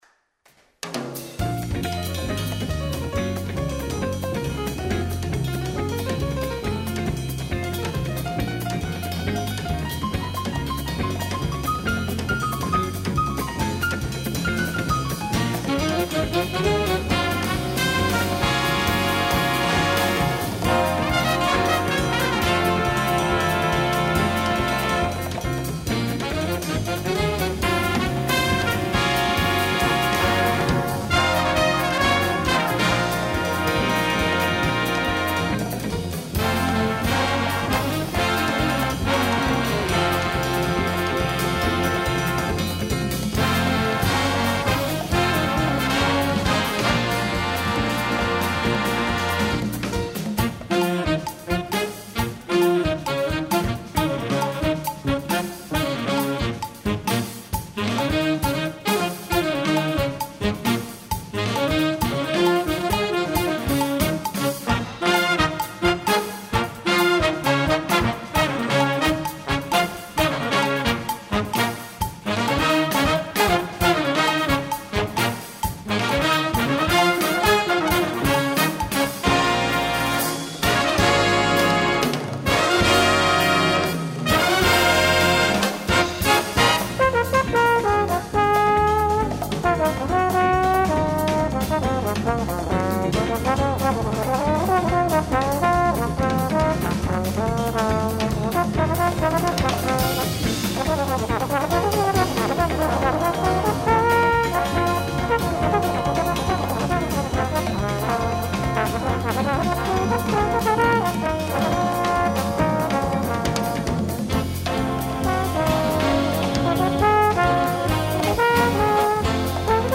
Jazzová témata / Jazz Themes